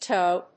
/tάʊ(米国英語)/